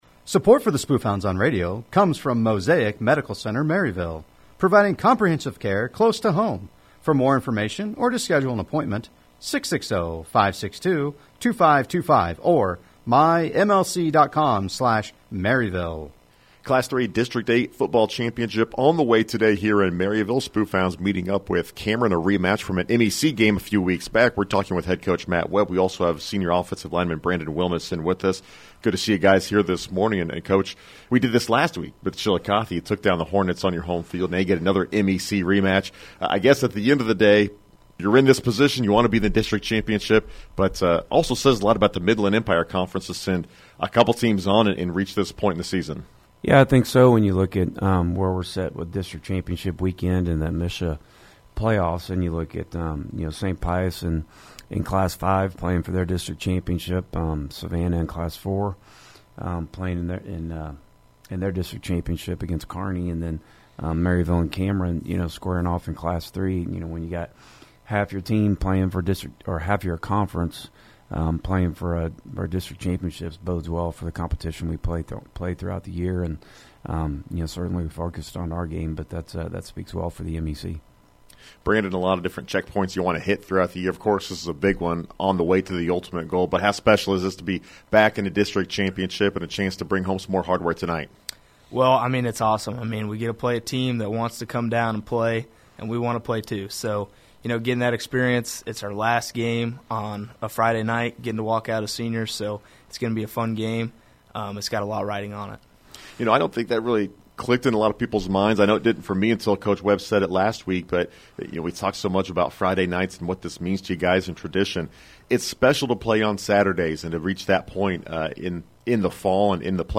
Local Sports